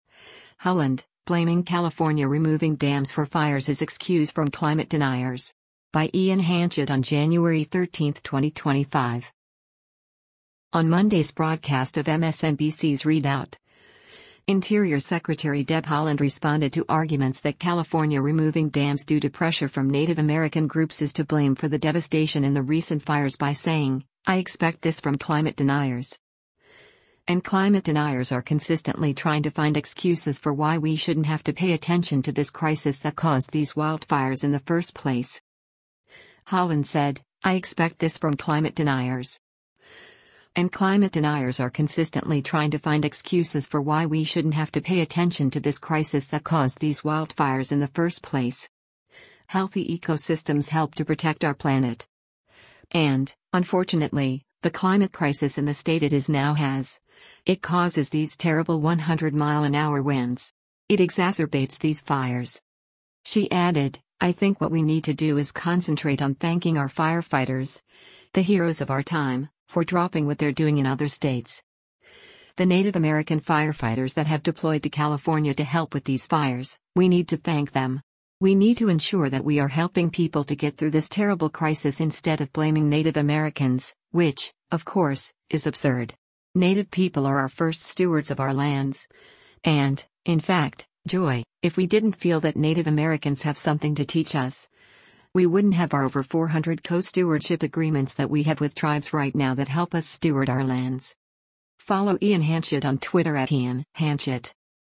On Monday’s broadcast of MSNBC’s “ReidOut,” Interior Secretary Deb Haaland responded to arguments that California removing dams due to pressure from Native American groups is to blame for the devastation in the recent fires by saying, “I expect this from climate deniers. And climate deniers are consistently trying to find excuses for why we shouldn’t have to pay attention to this crisis that caused these wildfires in the first place.”